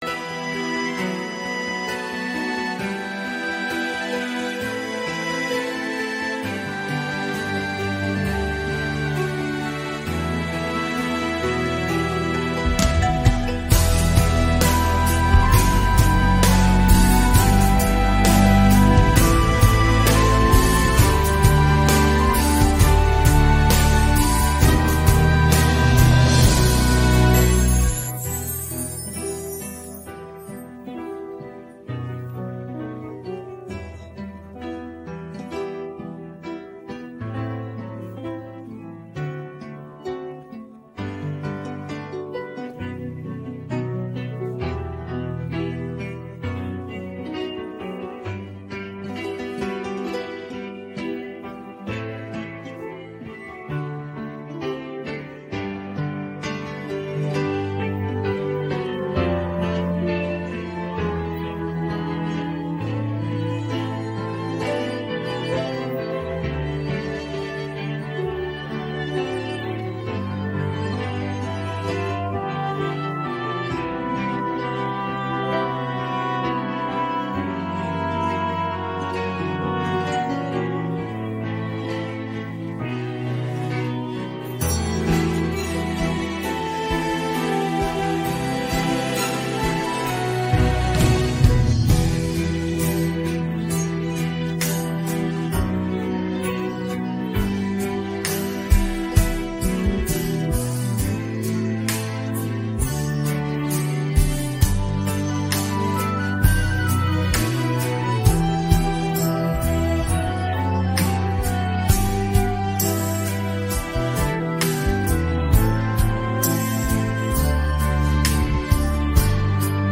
мінус караоке